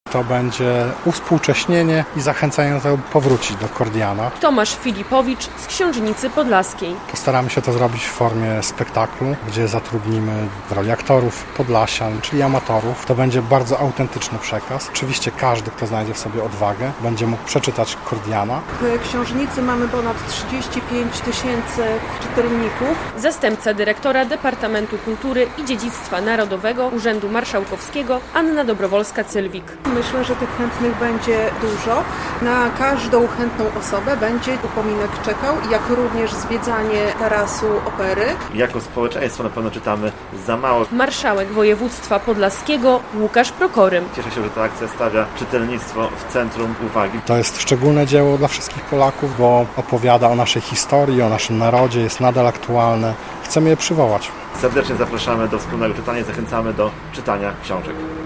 "Kordian" Juliusza Słowackiego na Narodowym Czytaniu w Białymstoku - relacja